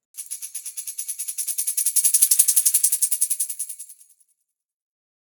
West MetroPerc (19).wav